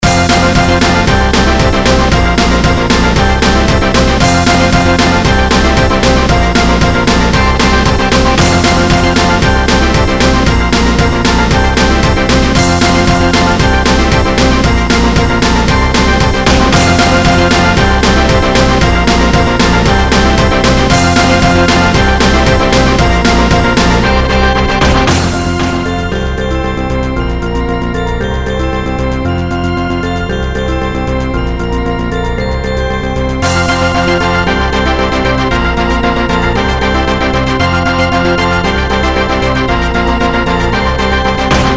this is pretty much a metal song made under microsoft gs wavetable synth midi thingy aka the same synthesizer used in old games like doom and system shock 1 (not sound blaster) still unfinished and currently just a loop
120 bpm
all 16 channels used
midi metal rock heavy oldschool